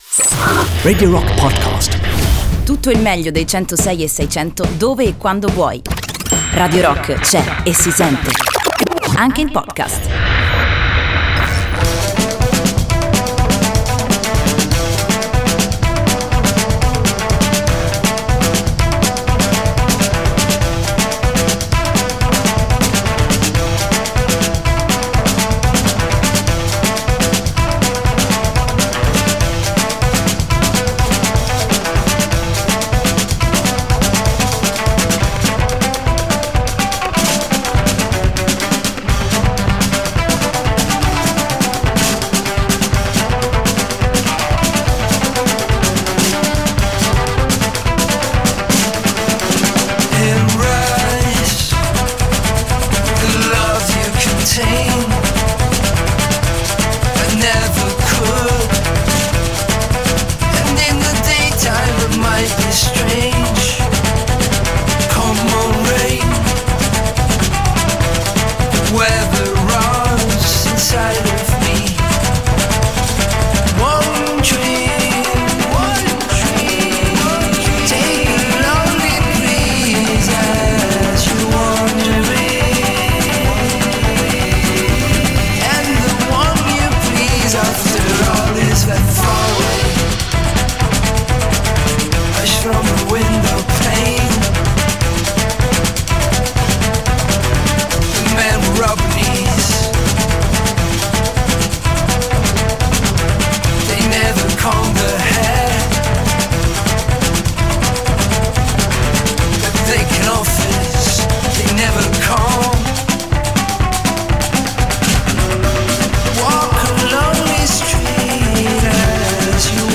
Intervista: I hate my village (28-01-19)